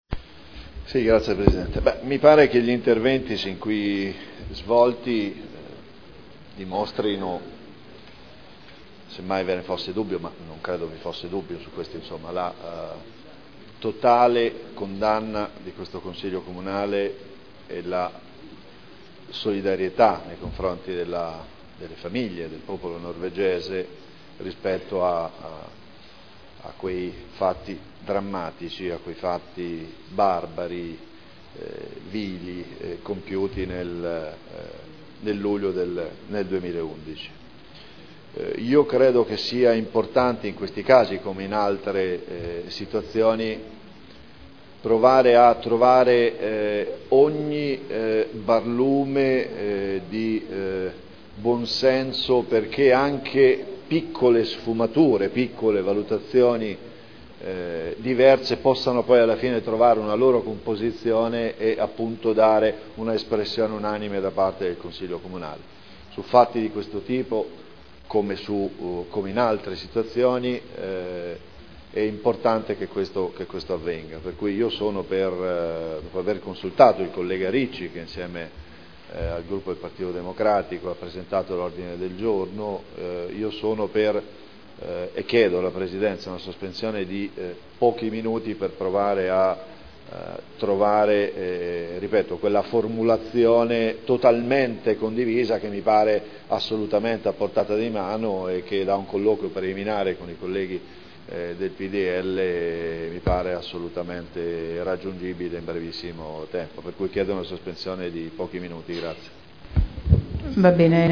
Paolo Trande — Sito Audio Consiglio Comunale